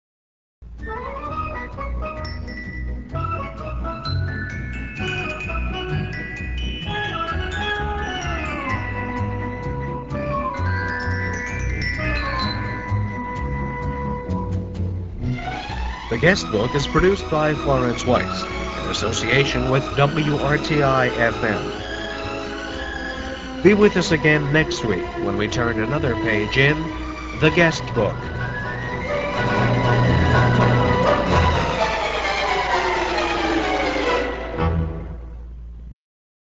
guestbook-close.mp3